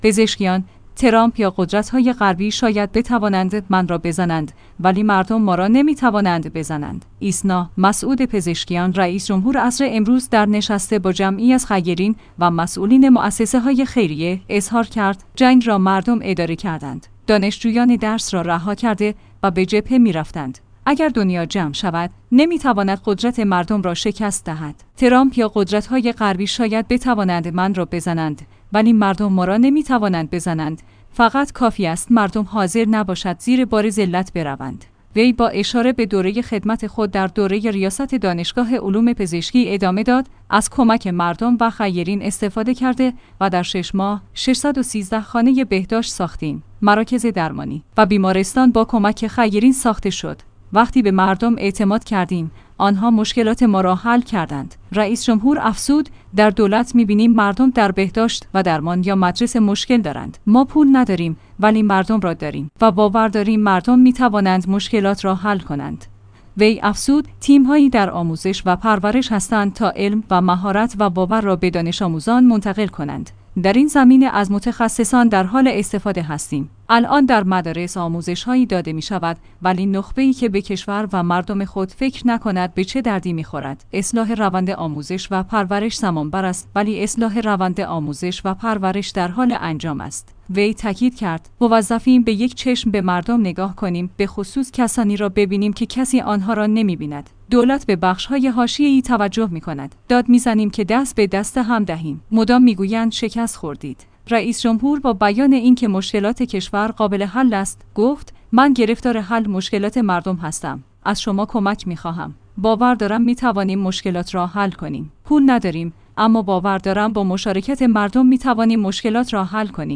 ایسنا/ مسعود پزشکیان رئیس جمهور عصر امروز در نشست با جمعی از خیرین و مسئولین موسسه‌های خیریه، اظهار کرد: جنگ را مردم اداره کردند.